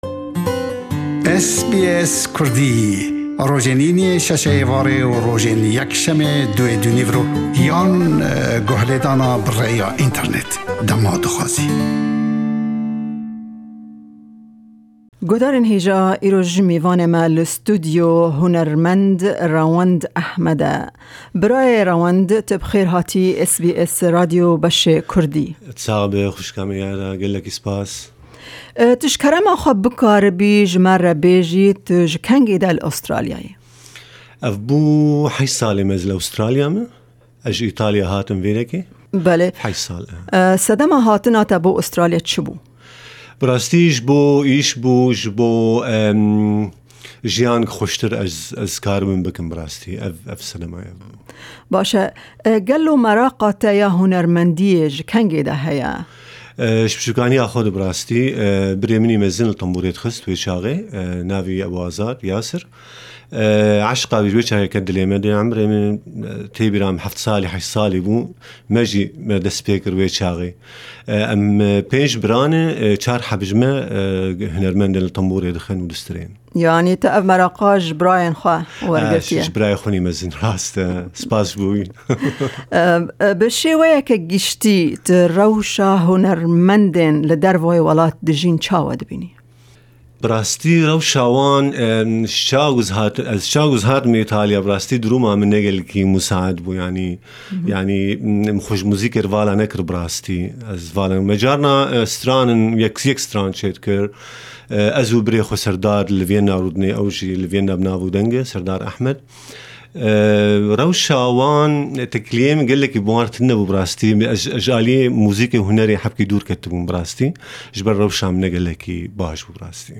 Em derbarê jiyana wî ya hunerî û jiyana li Australya bi wî re axifîn.